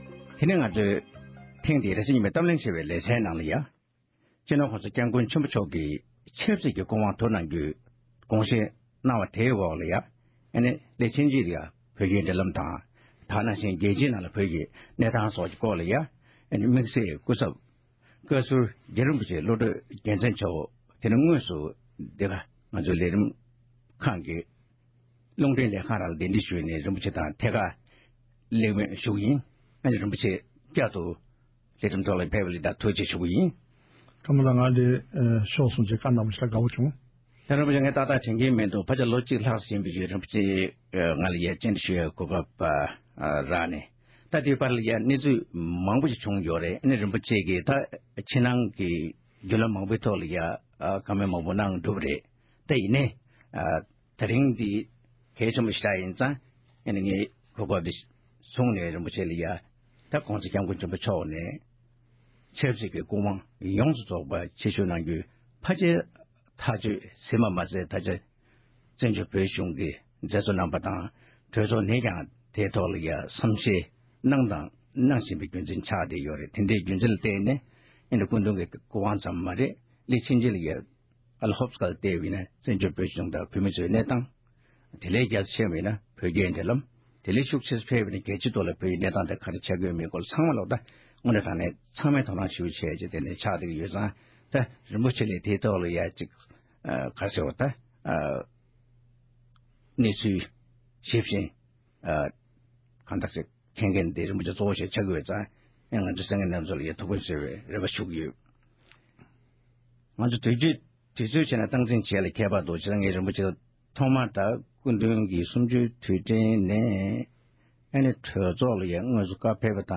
༸གོང་ས་མཆོག་གི་དམིགས་བསལ་སྐུ་ཚབ་བཀའ་ཟུར་རྒྱ་རི་རིན་པོ་ཆེ་མཆོག་གི་ལྷན་གླེང་བ།